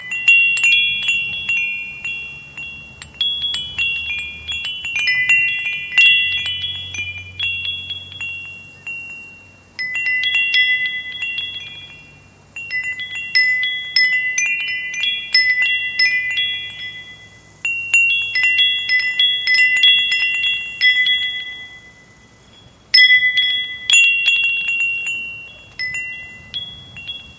Звуки дверных колокольчиков
Звон колокольчиков у входной двери — третий вариант